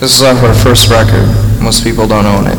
Play, download and share Cobain Speech original sound button!!!!
cubain-speech.mp3